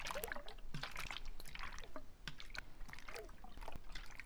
idleSlosh.wav